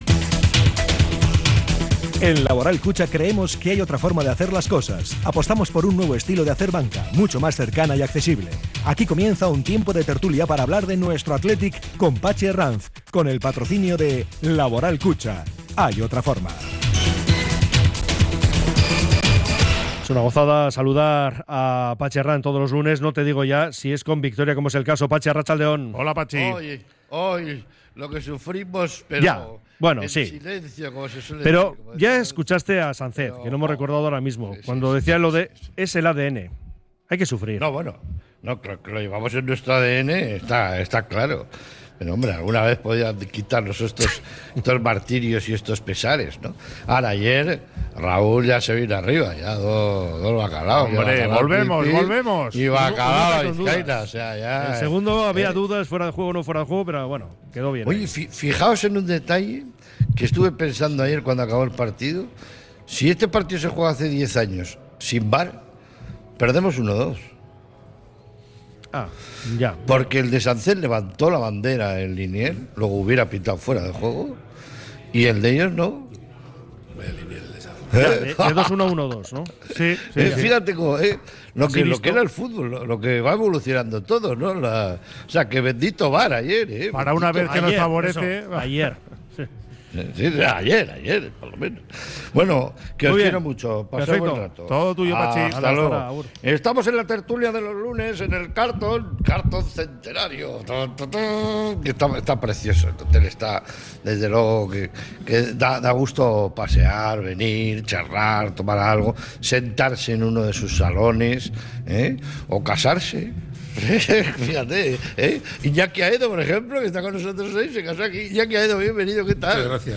sus invitados desde el hotel Carlton